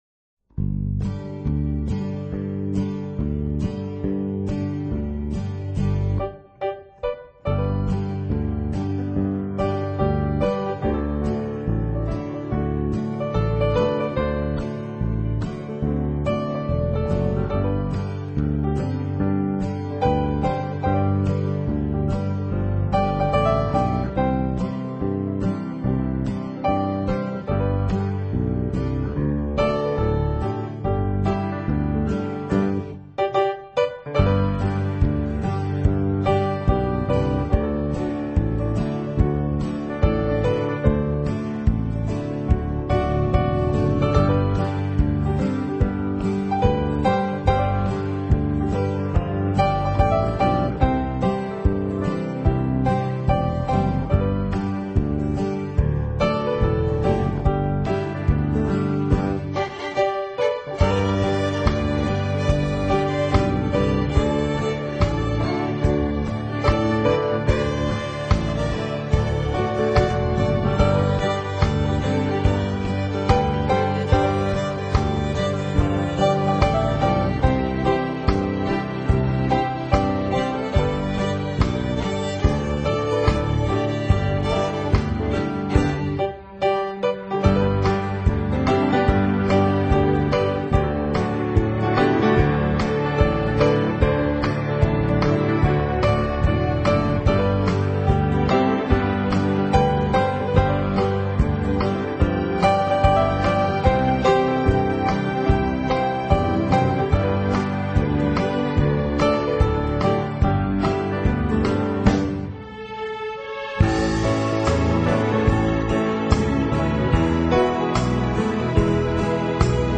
钢琴专辑
音乐类型：New Age
在背景音乐中，小提琴、口哨、声学吉他、曼陀铃、
在这辑音乐中，无论是主题演奏还是间奏，始终出色，钢琴引领主题，做到了深入浅